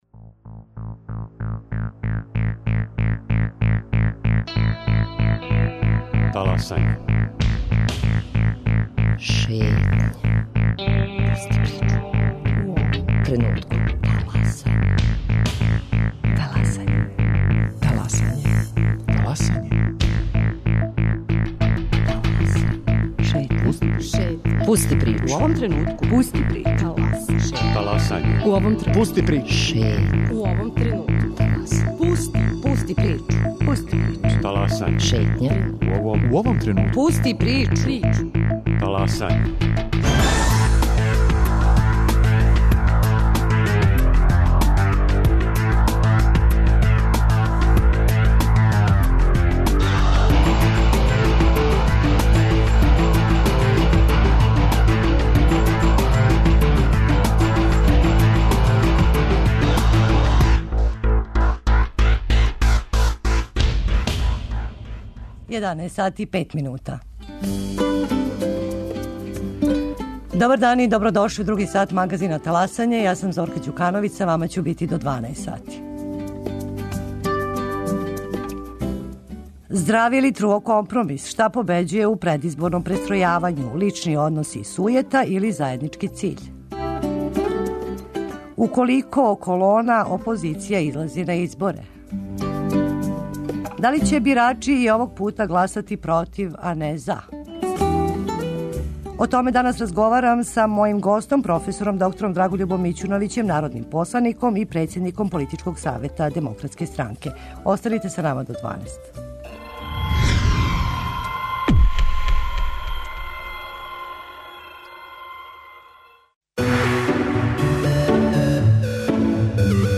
Гост професор Драгољуб Мићуновић, народни посланик и председник Политичког савета Демократске странке.